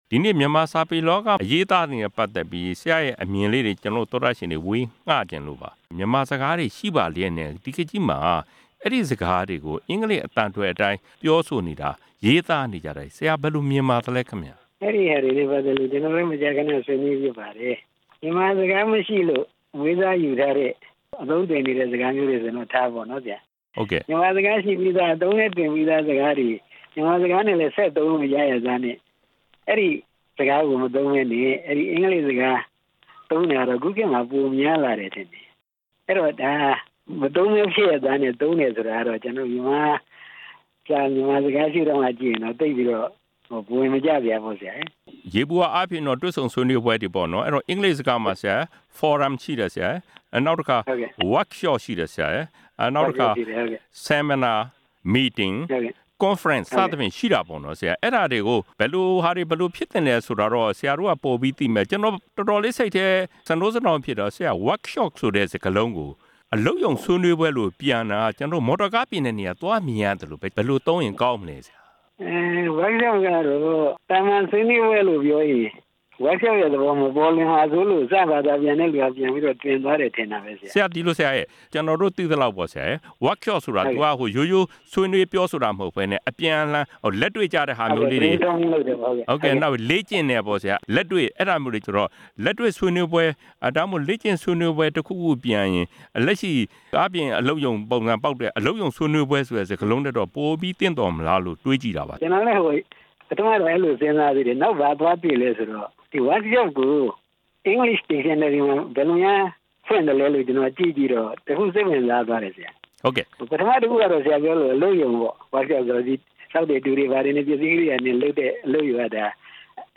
မြန်မာ့မီဒီယာလောကမှာ မှားယွင်းပြောဆိုရေးသားနေကြတဲ့အကြောင်း မေးမြန်းချက်